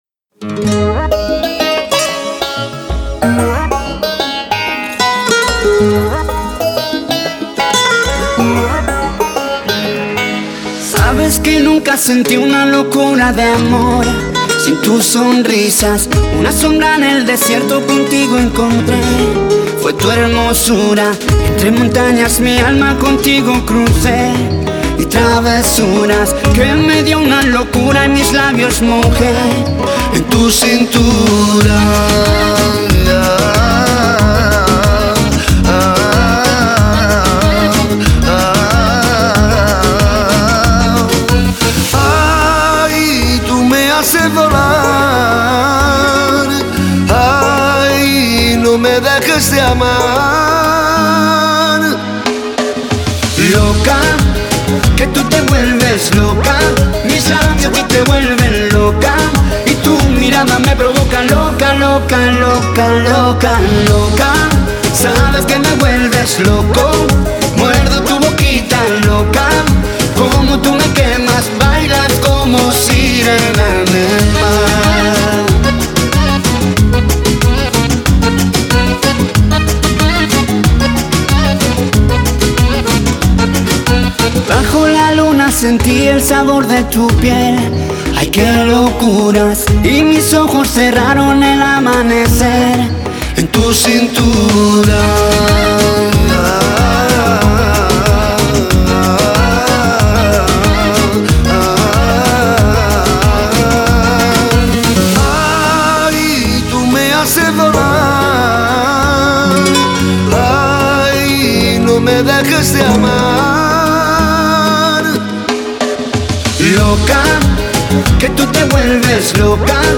зажигательная композиция в жанре реггетон